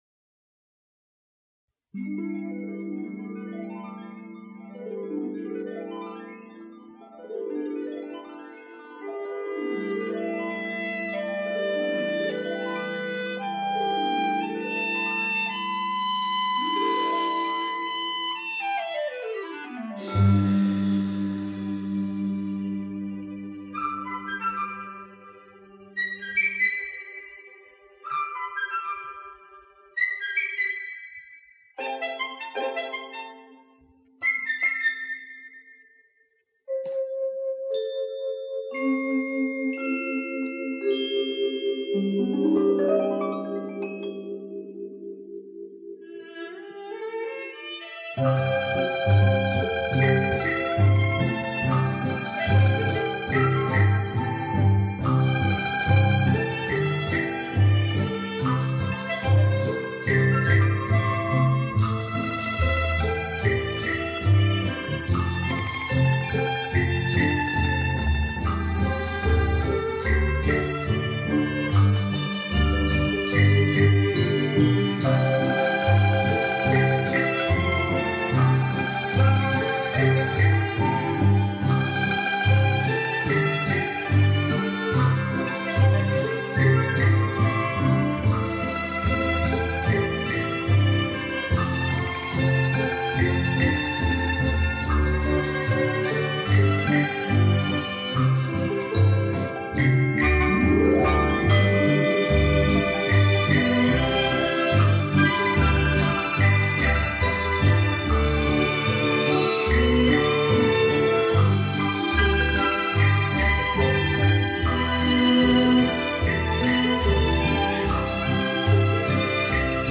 曲调优美动听，朴实感人。